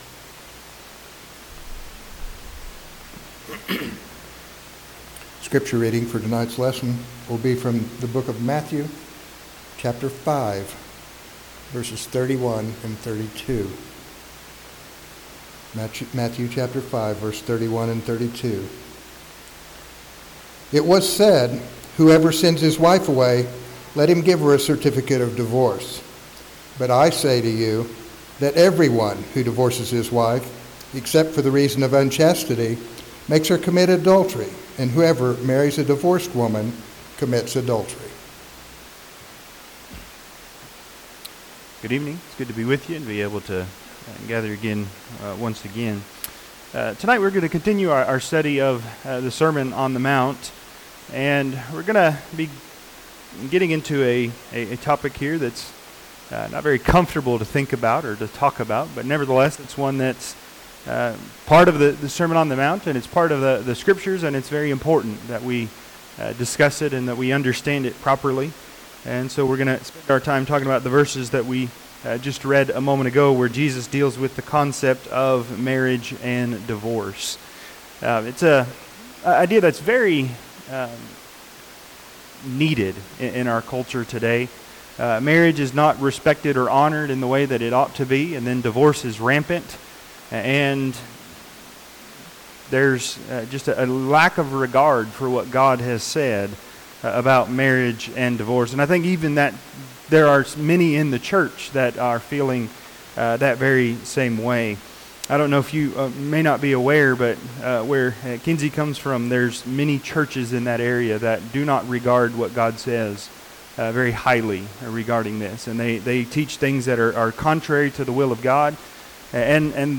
Matthew 5:31-32 Service Type: Sunday PM Find out what the LORD GOD says about Marriage